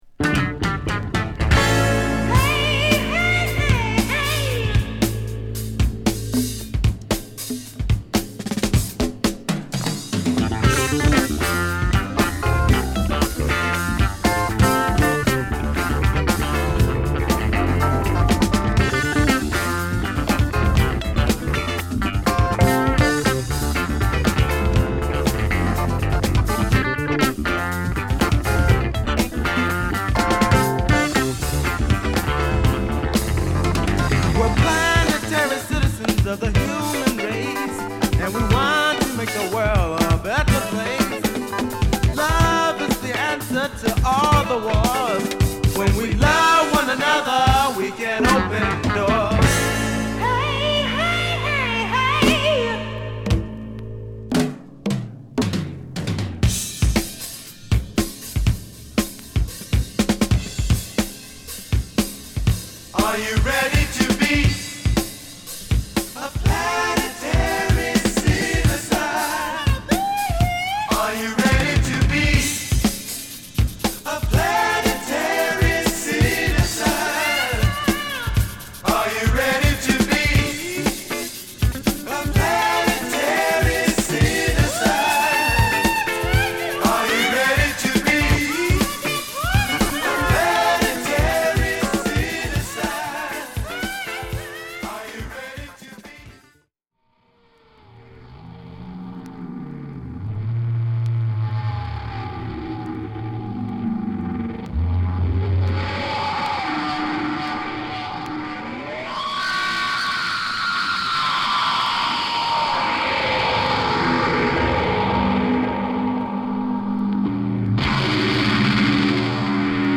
スペイシーなサウンドのプログレッシブ・ジャズロックロックを披露！